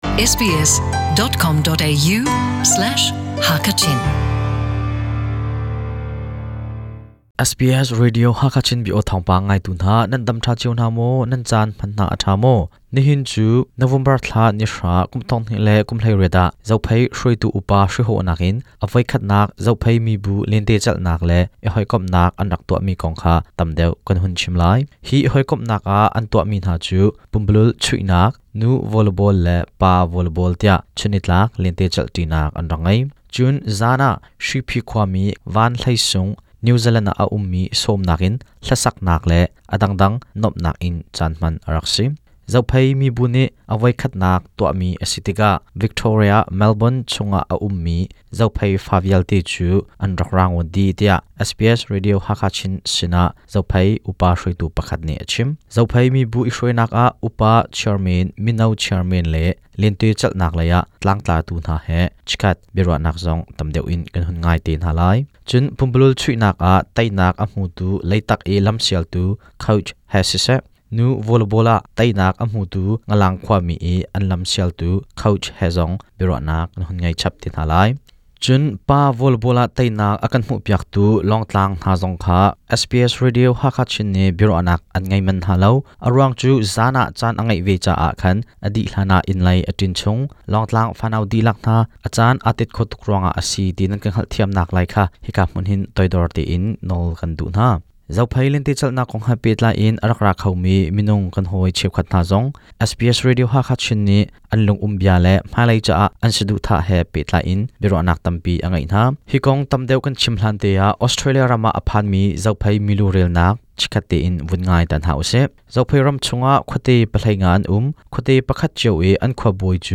SBS Radio Hakha Chin zong hi Nisunglawi ah a rak kal ve.